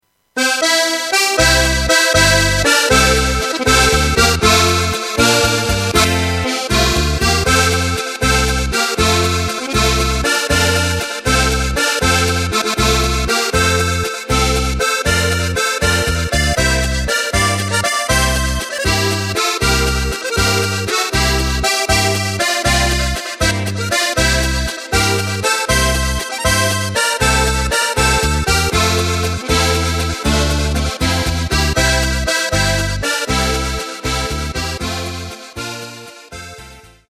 Takt:          3/4
Tempo:         237.00
Tonart:            F
Flotter Walzer aus dem Jahr 1986!
Playback mp3 Demo